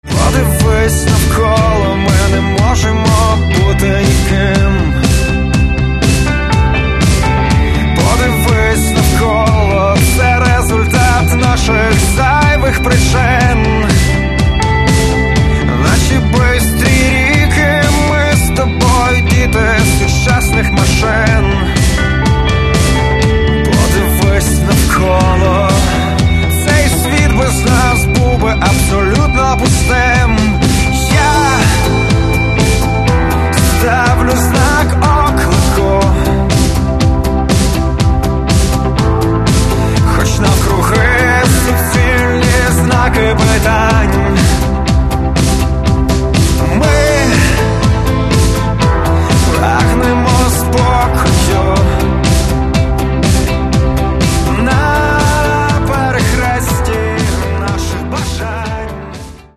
Rock & Alternative
Catalogue -> Rock & Alternative -> Simply Rock
more rock'n'roll, clear, driving